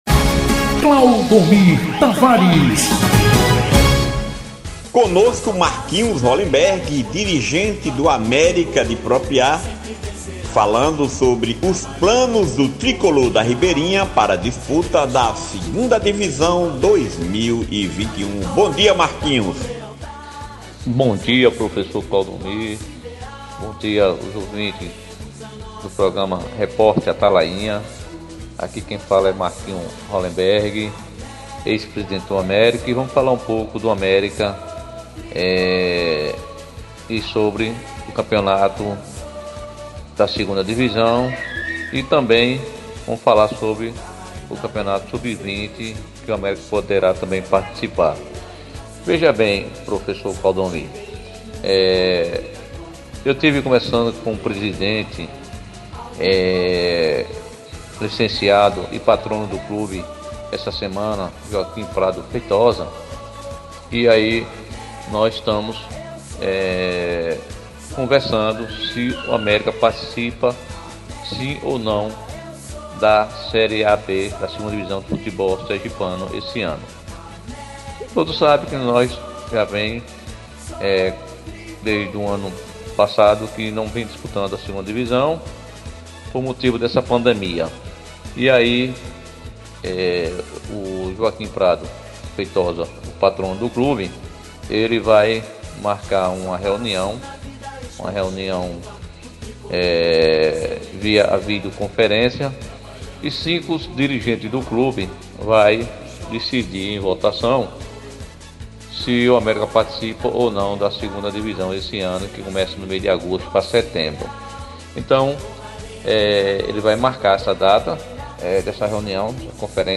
Em entrevista ao programa Reporter Atalainha